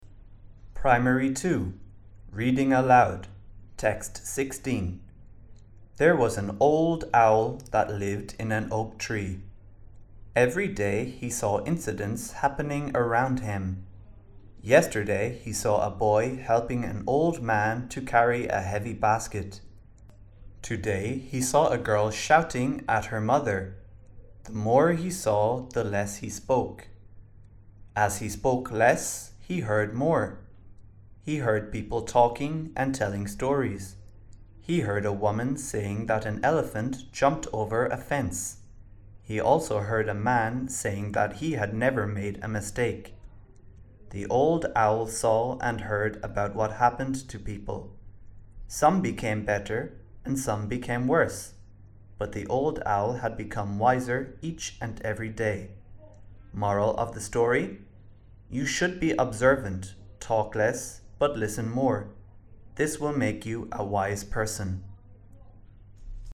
Reading Aloud
แบบฝึกการอ่าน และการออกเสียงภาษาอังกฤษ ปีการศึกษา 2568